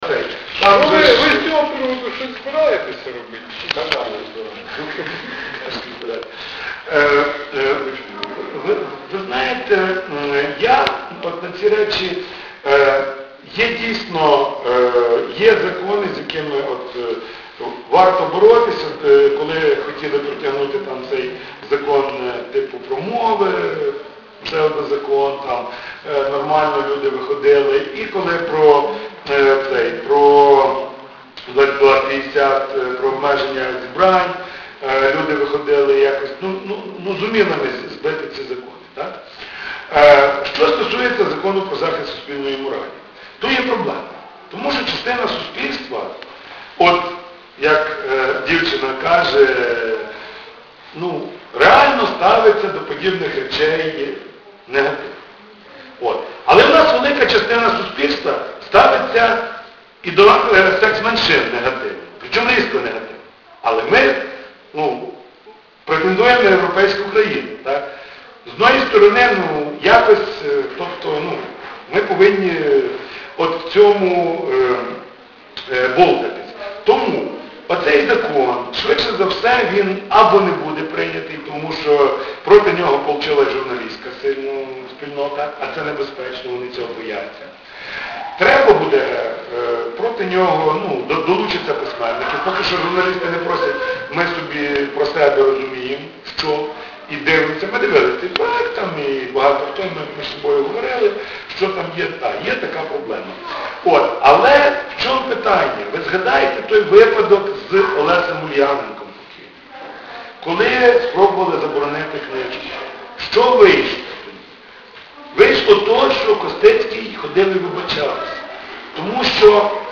Он встретился с журналистами в пресс-центре Sumynews и с сумчанами в муниципальной галерее.